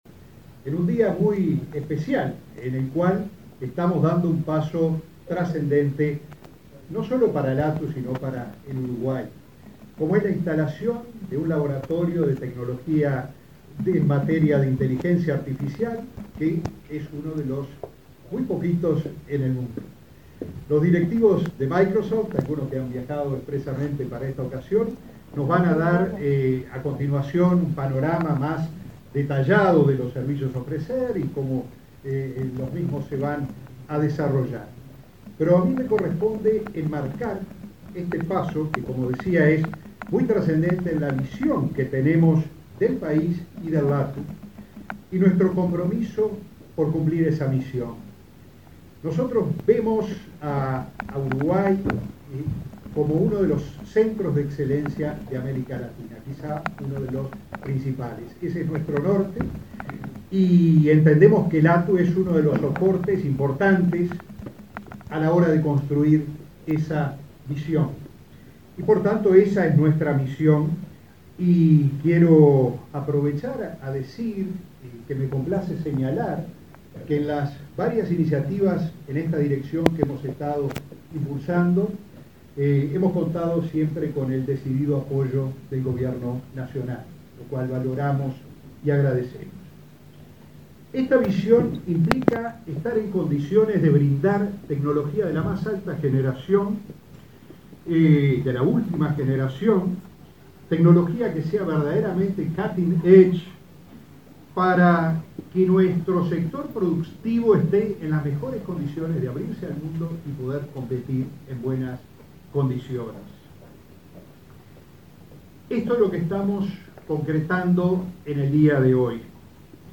Palabras de autoridades en el LATU
El presidente del Laboratorio Tecnológico del Uruguay (LATU), Ruperto Long, y el ministro de Industria, Omar Paganini, participaron en la inauguración